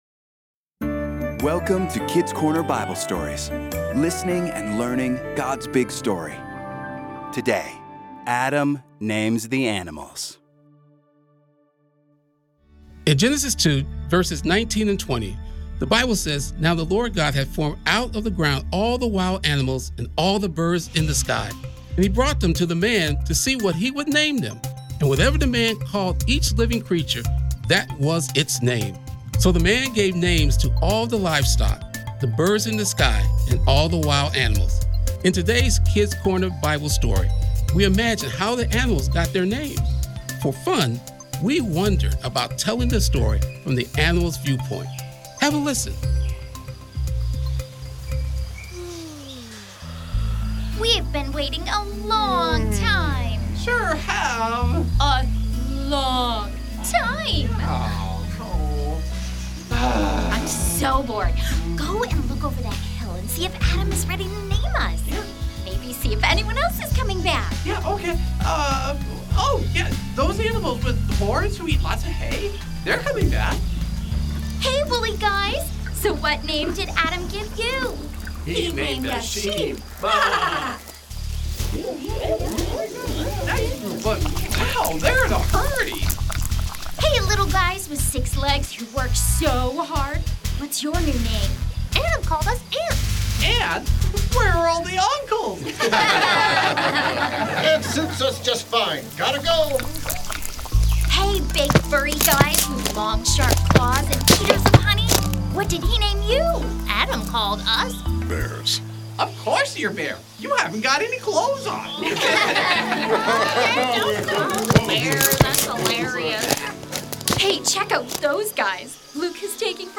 Adam Names the Animals | Audio Bible Stories | Kids Corner
Just for fun, we told this story from the viewpoint of the animals themselves!